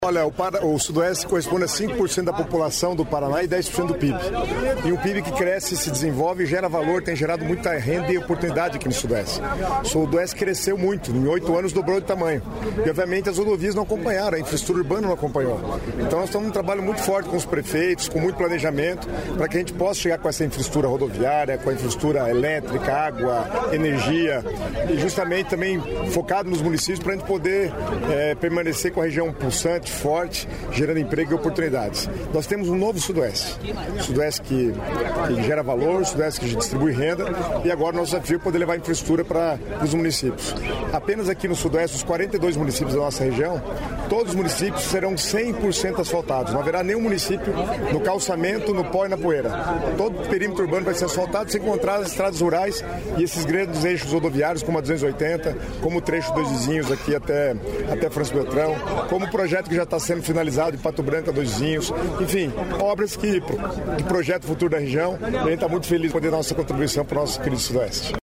Sonora do secretário Estadual das Cidades, Guto Silva, sobre pedra fundamental da modernização de duas rodovias no Sudoeste